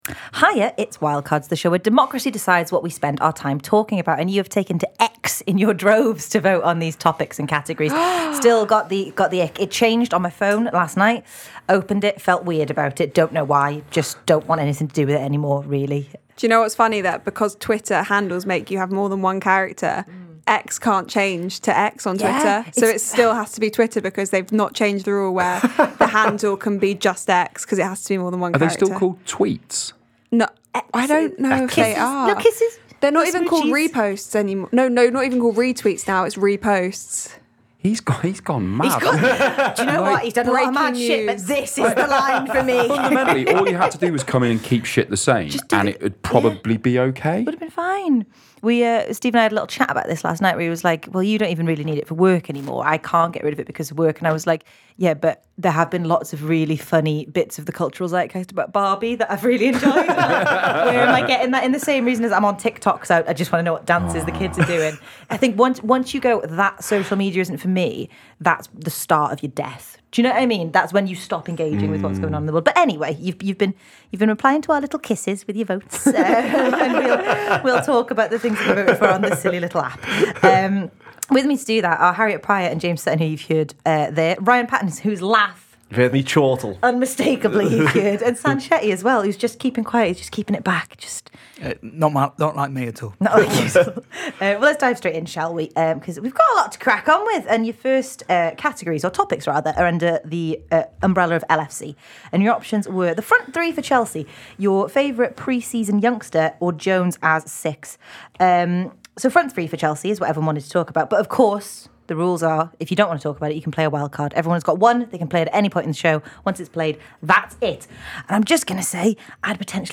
The Anfield Wrap’s Wildcards panel discuss Liverpool’s front three to start the season, wildest transfer fees, Stuart Broad and Barbie/Oppenheimer.
Below is a clip from the show – subscribe for more on Liverpool’s front three to start the season…